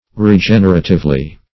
Regeneratively \Re*gen"er*a*tive*ly\, adv. So as to regenerate.
regeneratively.mp3